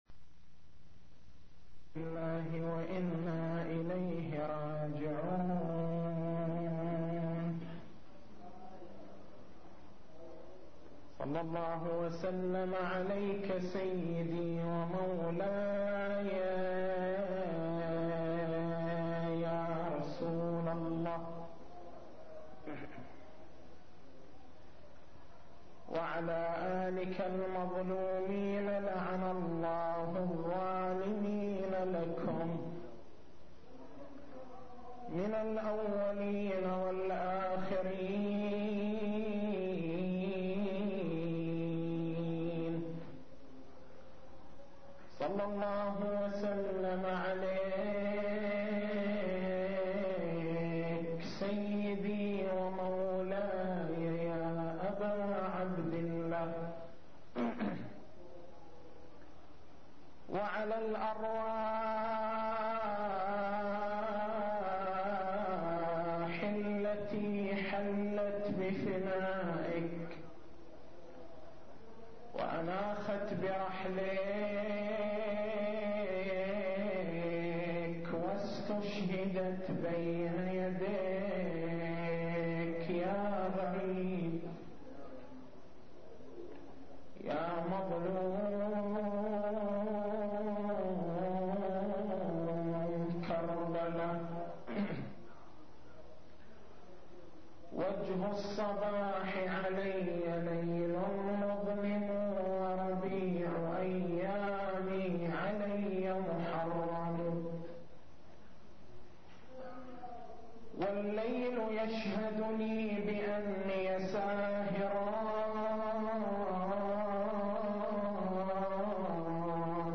تاريخ المحاضرة: 02/01/1423 نقاط البحث: الدليل القرآني الدليل الروائي الدليل العقائدي الدليل التاريخي التسجيل الصوتي: تحميل التسجيل الصوتي: شبكة الضياء > مكتبة المحاضرات > محرم الحرام > محرم الحرام 1423